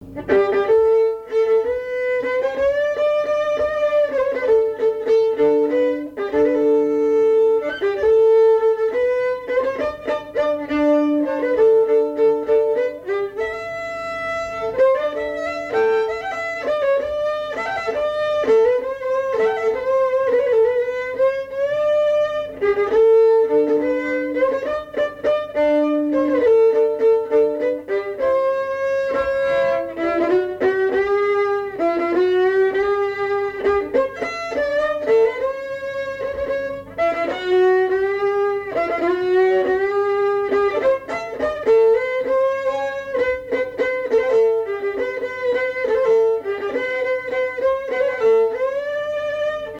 Mémoires et Patrimoines vivants - RaddO est une base de données d'archives iconographiques et sonores.
danse : valse
Pièce musicale inédite